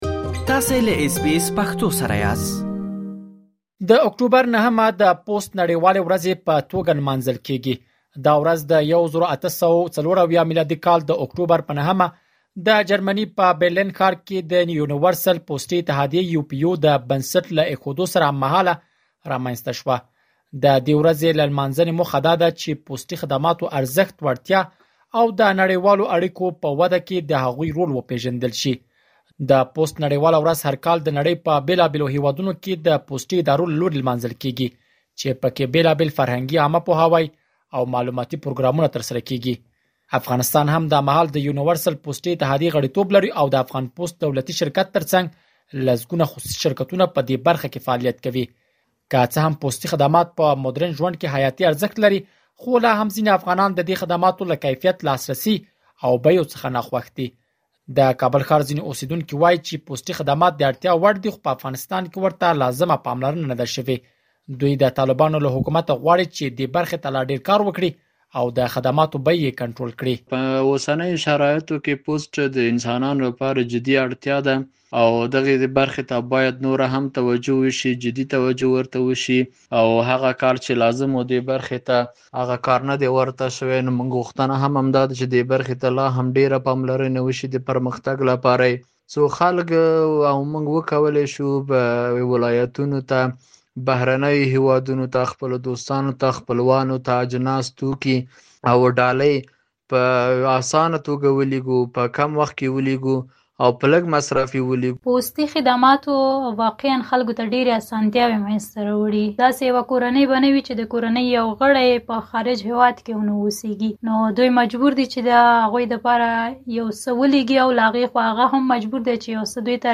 مهرباني وکړئ نور مالومات په راپور کې واورئ.